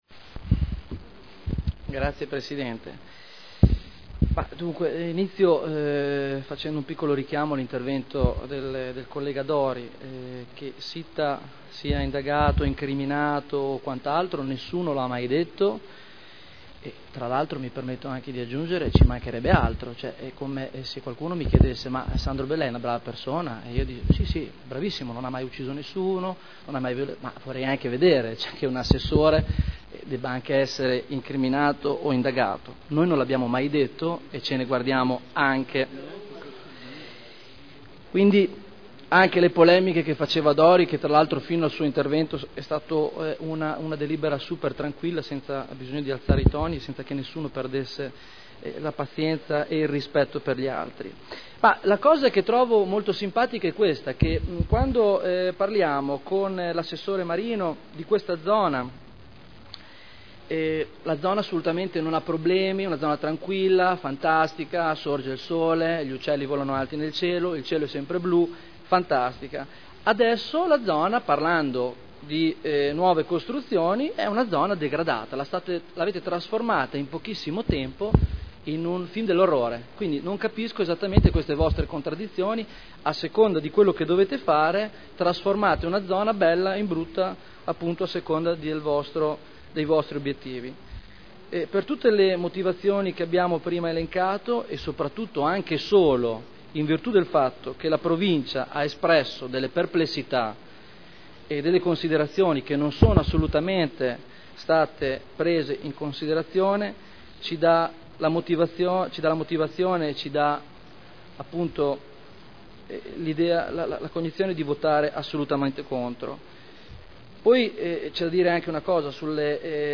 Stefano Barberini — Sito Audio Consiglio Comunale